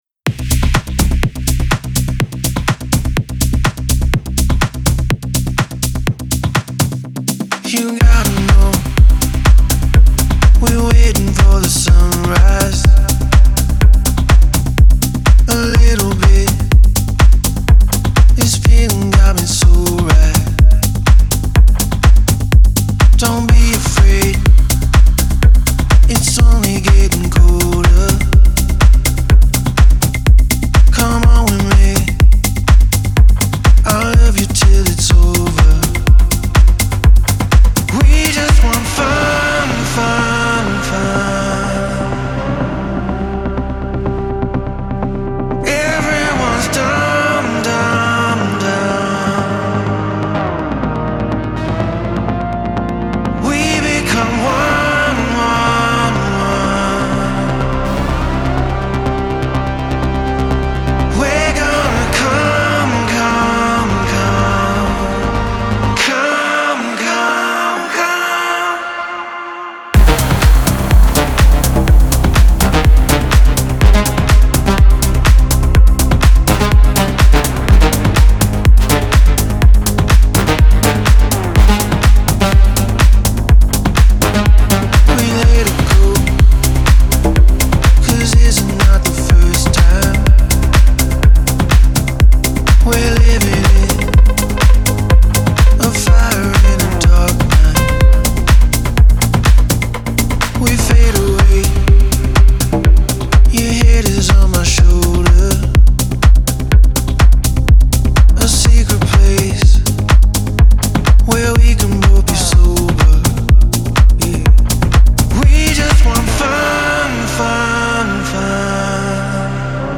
• Жанр: House, Techno